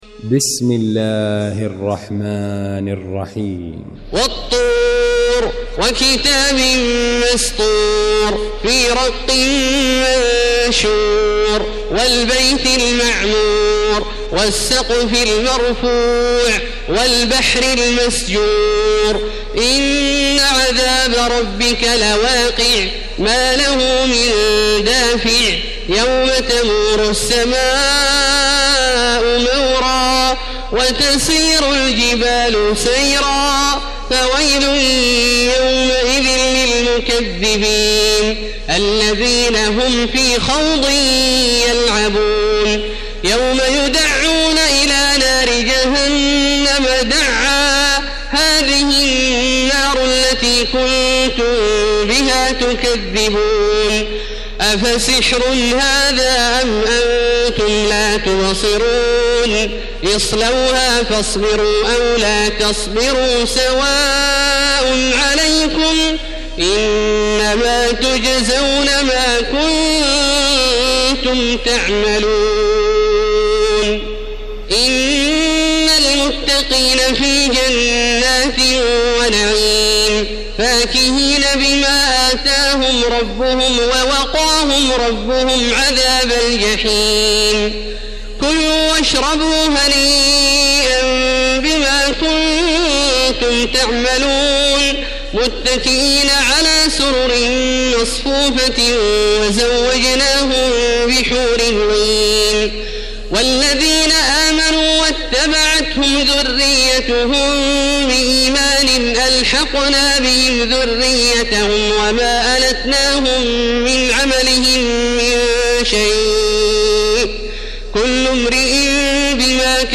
المكان: المسجد الحرام الشيخ: فضيلة الشيخ عبدالله الجهني فضيلة الشيخ عبدالله الجهني الطور